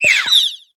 Cri de Goupilou dans Pokémon HOME.